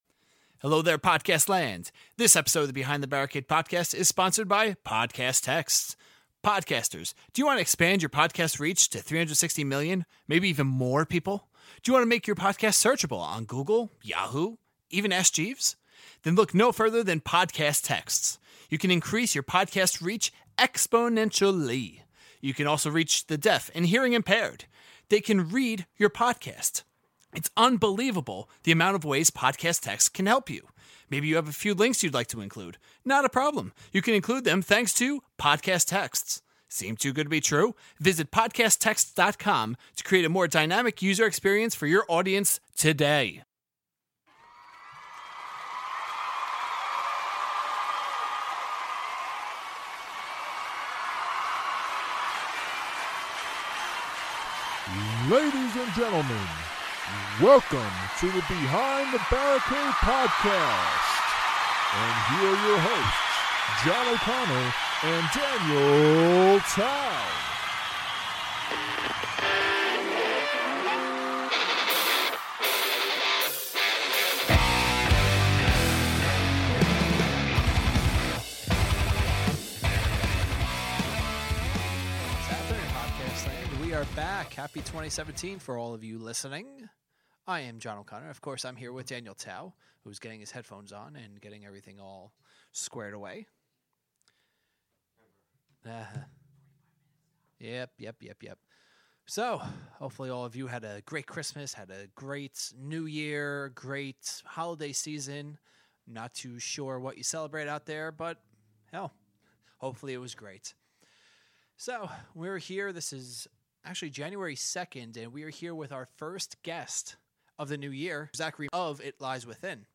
Post-Interview Song: Starscream